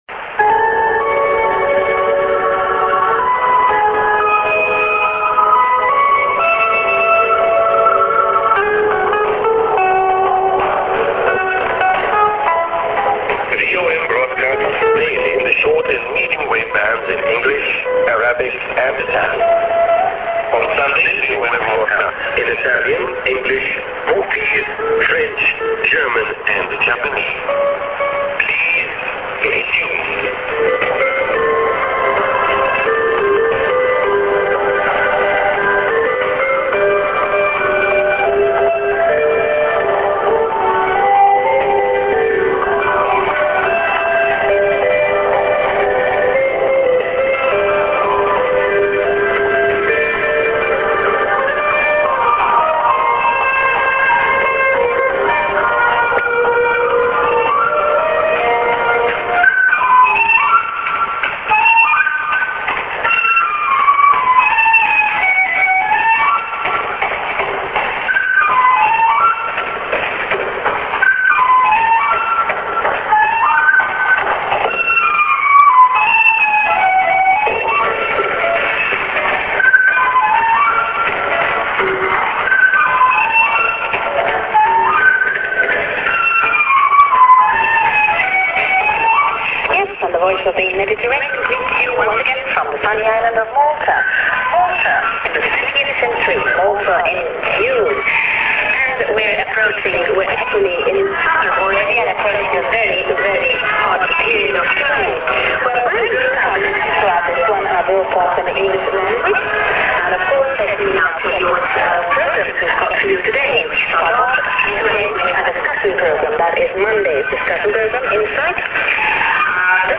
St.Eng. ID+SKJ(man)->music->prog(women)->ANN(man)